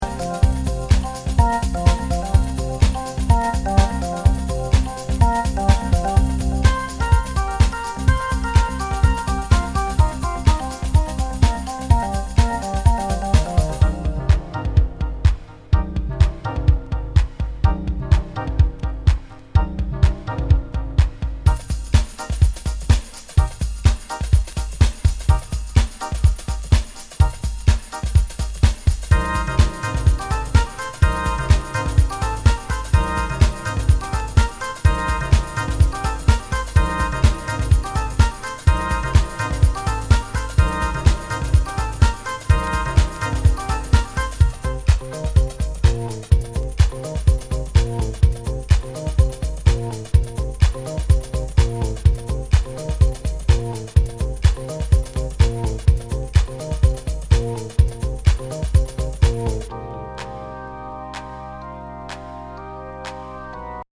HOUSE/TECHNO/ELECTRO
ナイス！ユーロ・ハウス！